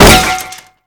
ap_hit_veh2.wav